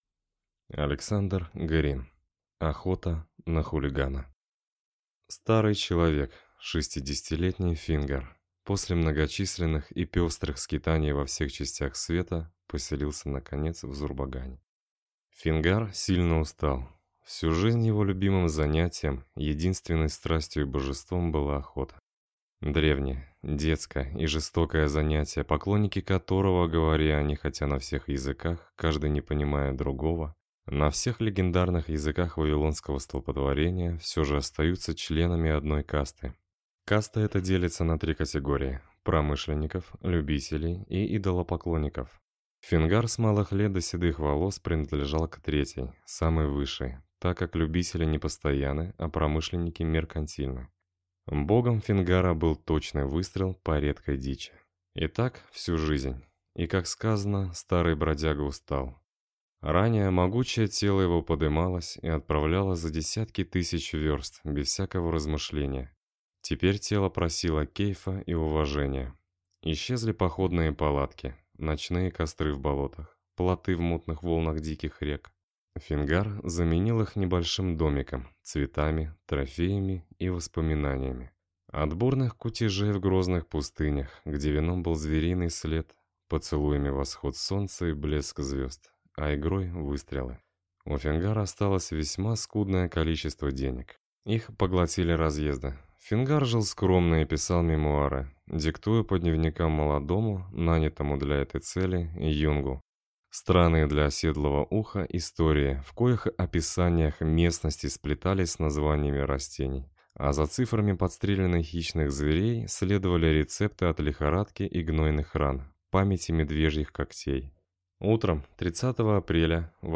Аудиокнига Охота на хулигана | Библиотека аудиокниг